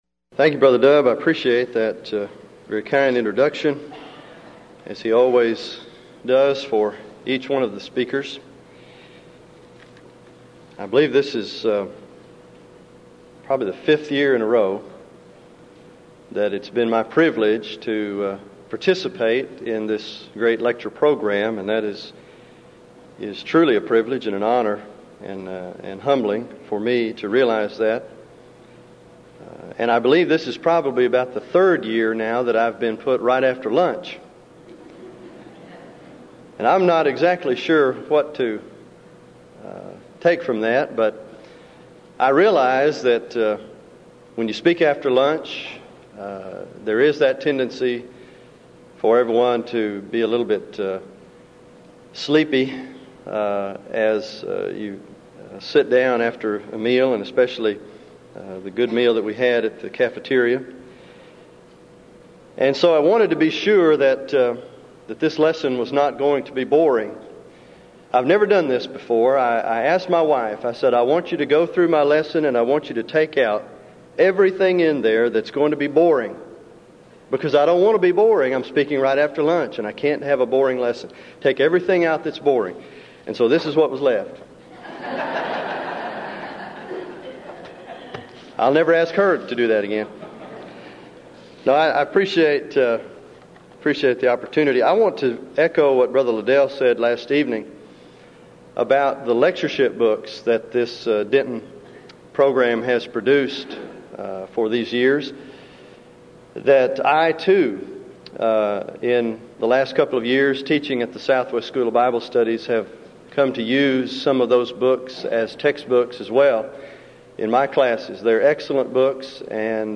Series: Denton Lectures